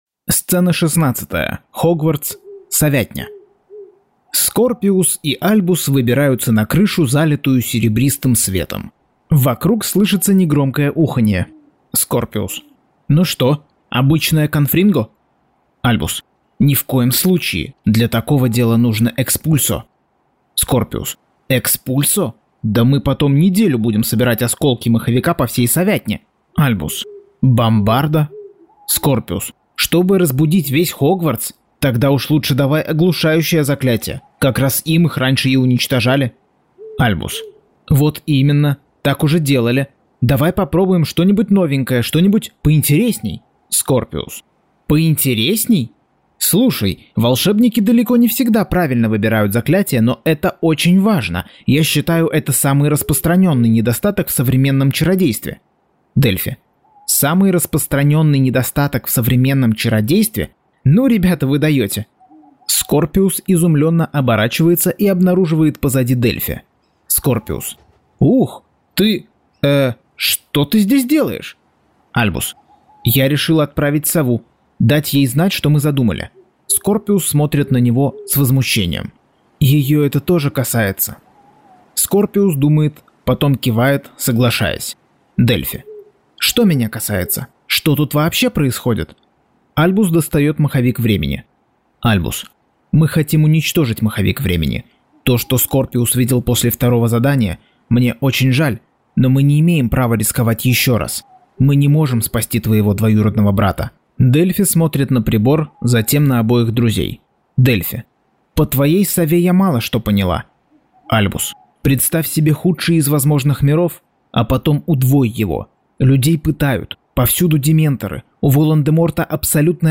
Аудиокнига Гарри Поттер и проклятое дитя. Часть 48.